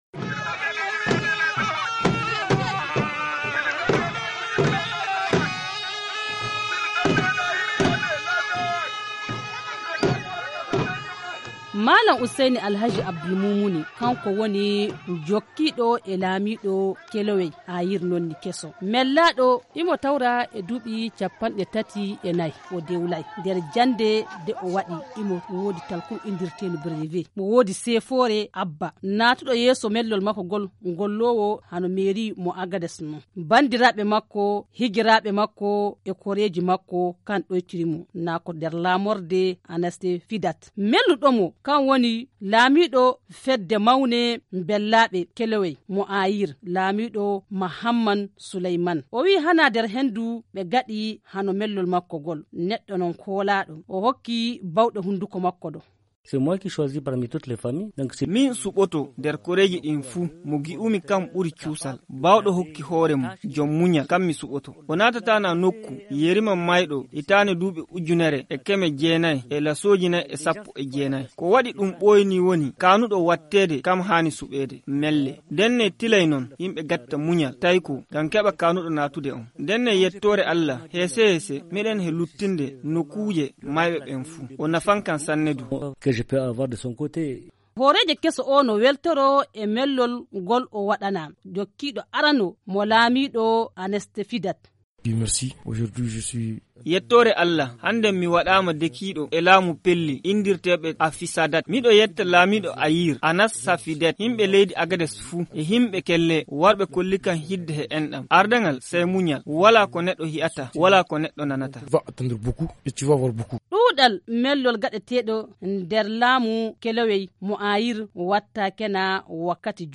Journal en fulfuldé
FUL-REP MAG INTRONISATION AGADEZ 2606 ok.mp3